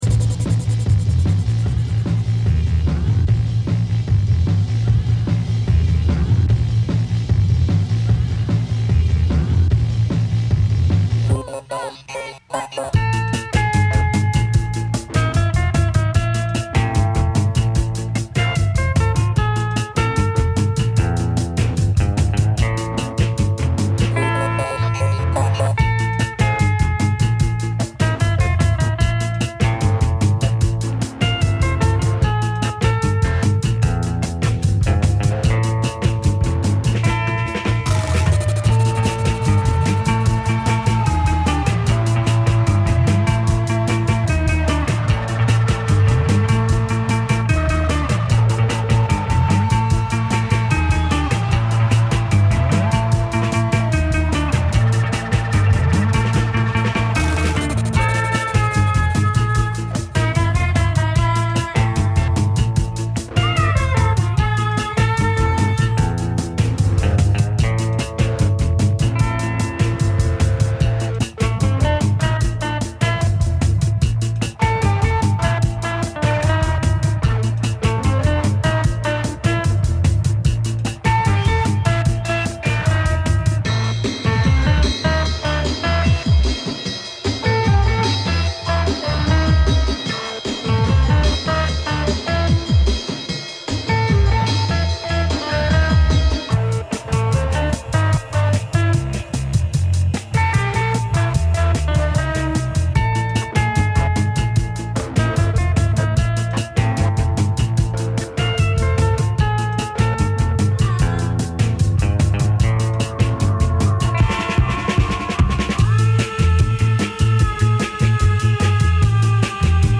Category: Up Tempo